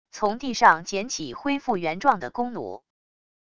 从地上捡起恢复原状的弓弩wav音频